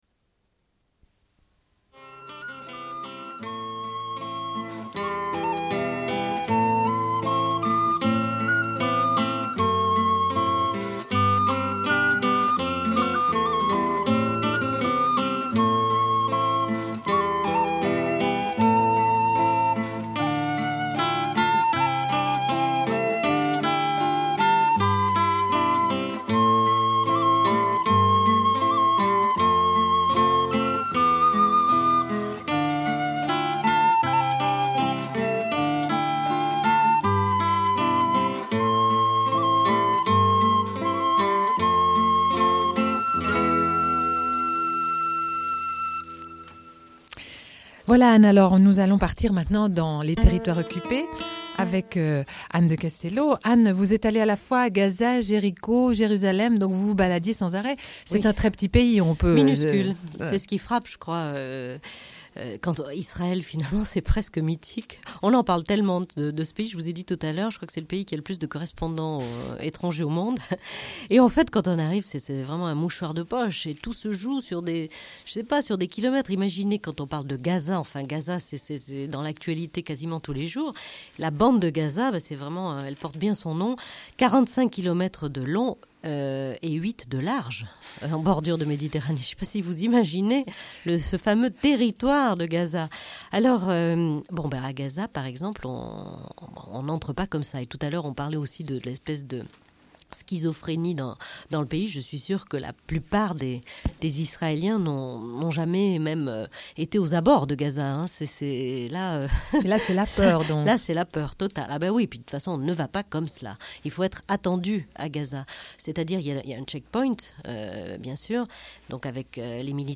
Une cassette audio, face A30:54